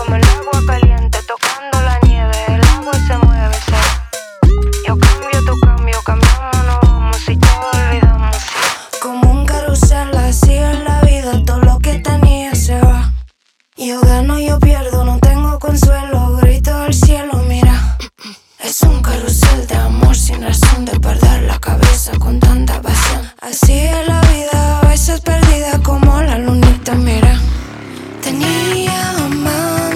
Страстные ритмы латино
Жанр: Латино